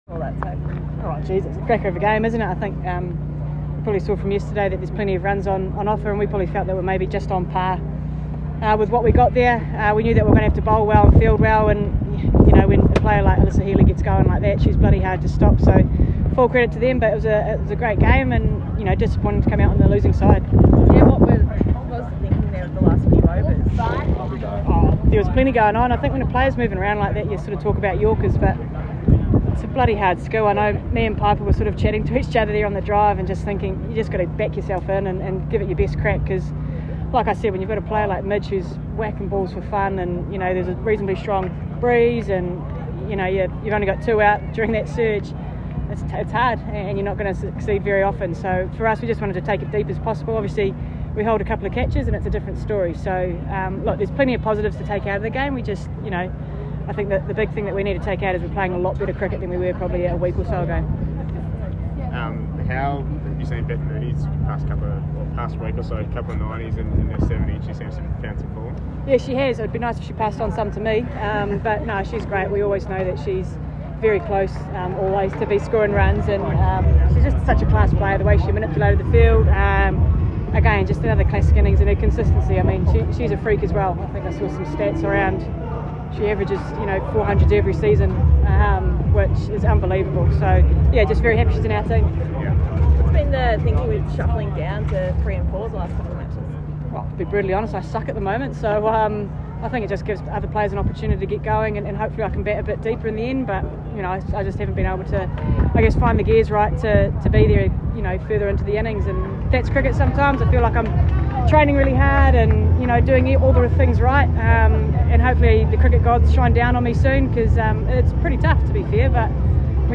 Scorchers captain Sophie Devine, speaking after Perth’s loss to Sydney Sixers at the CitiPower Centre this afternoon.